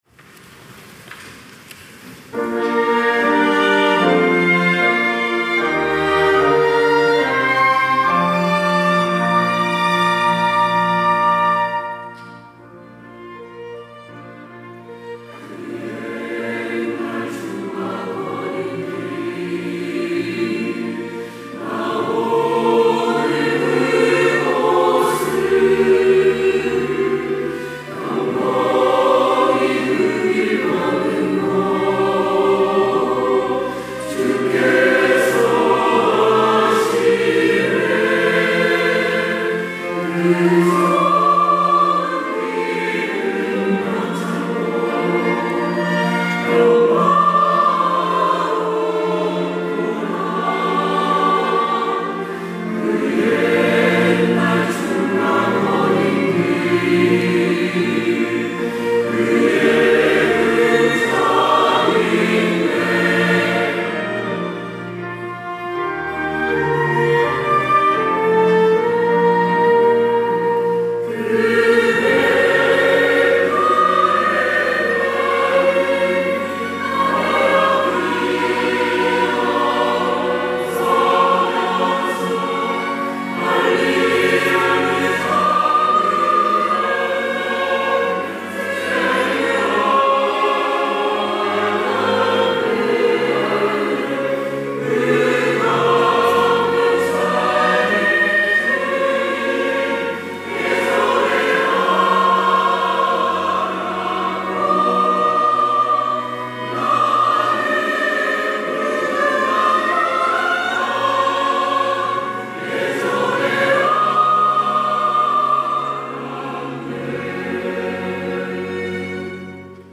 호산나(주일3부) - 주 걸으신 길 나 걸었네
찬양대